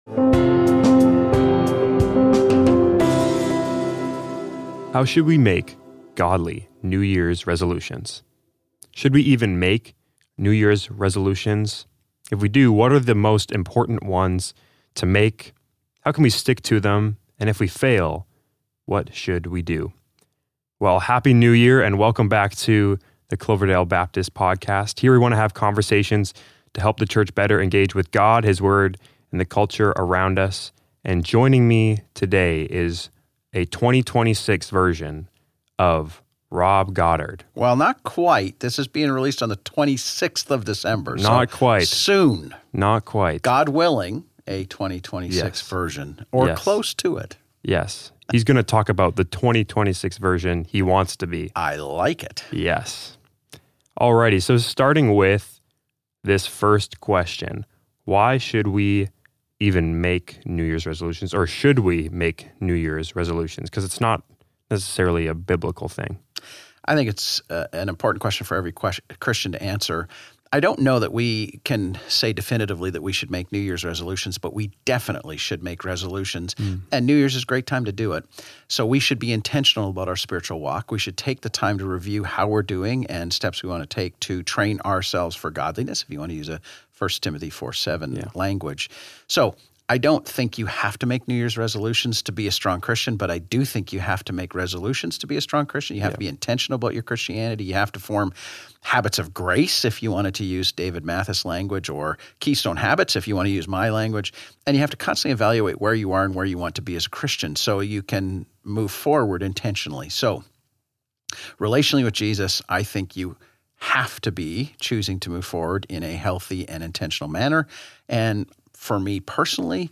The conversation focuses on what resolutions are most important, how to have the proper attitude toward resolutions, and what to do if we fail in our resolutions.